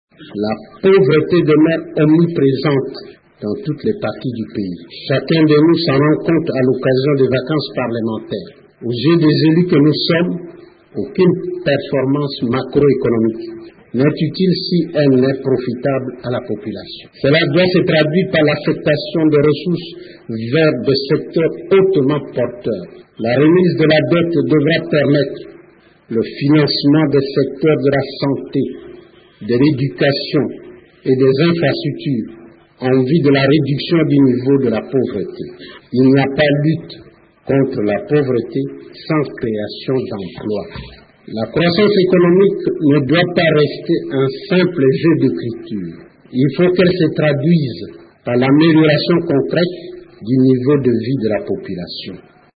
Il l’a souligné dans son discours d’ouverture: